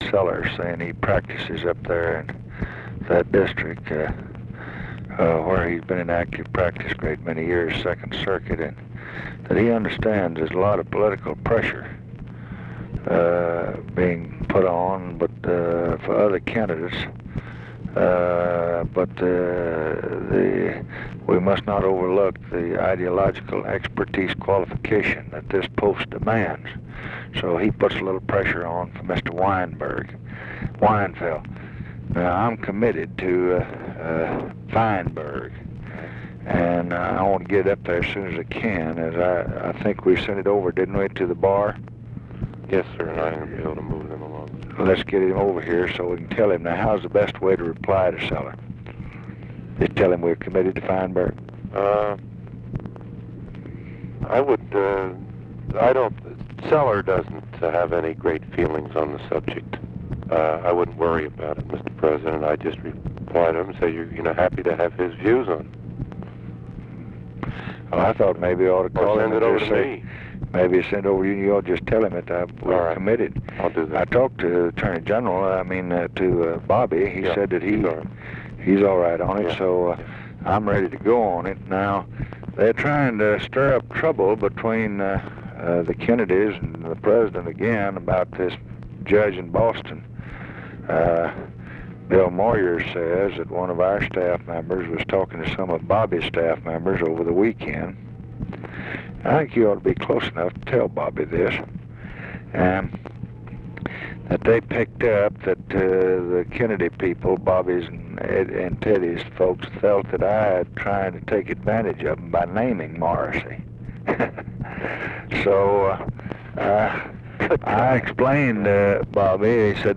Conversation with NICHOLAS KATZENBACH, September 29, 1965
Secret White House Tapes